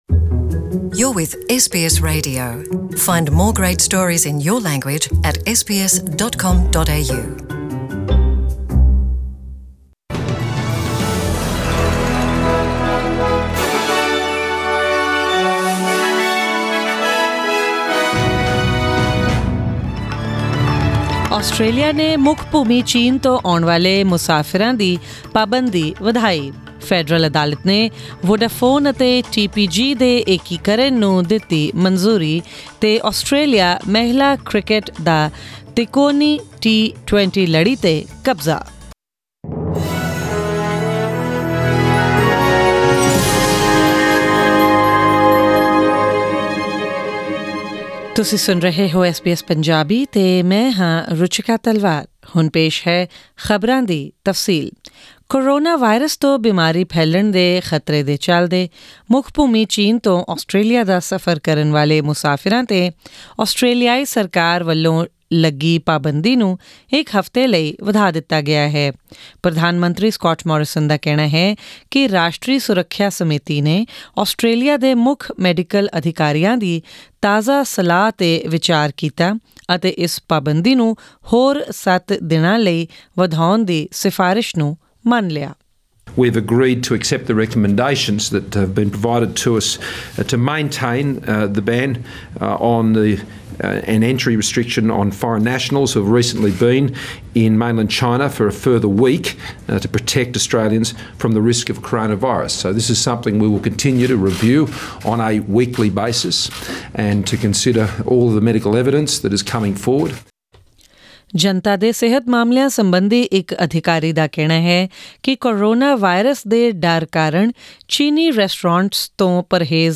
Australian News in Punjabi: 13 February 2020